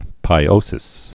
(pī-ōsĭs)